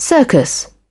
Transcription and pronunciation of the word "circus" in British and American variants.